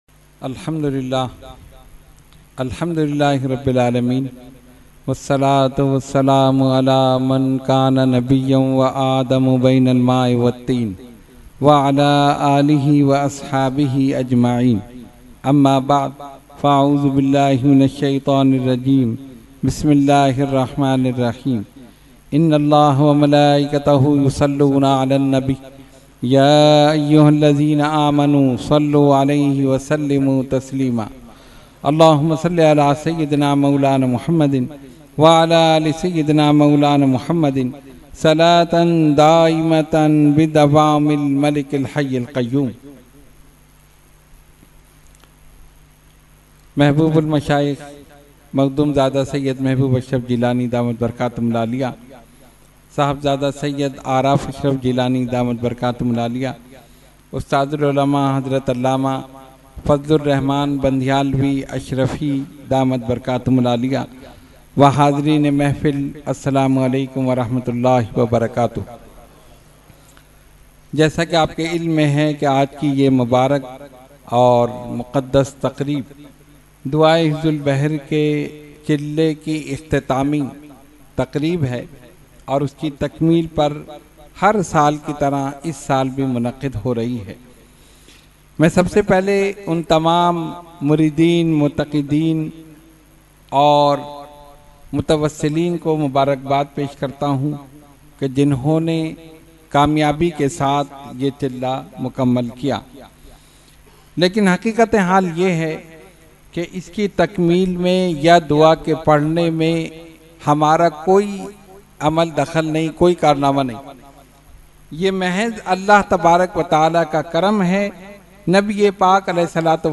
Ikhtitaam e Dua e Hizbul Bahar Mehfil held on 30 April 2022 at Dargah Alia Ashrafia Ashrafabad Firdous Colony Gulbahar Karachi.
Category : Speech | Language : UrduEvent : Khatam Hizbul Bahr 2024